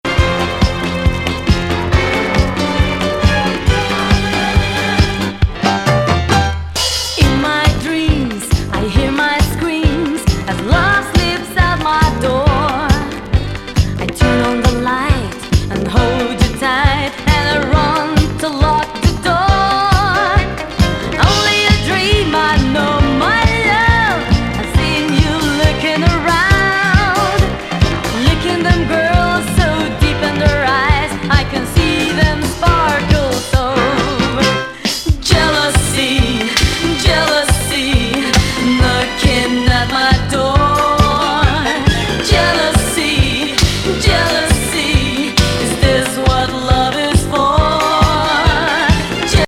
国内企画モノ・ディスコ!!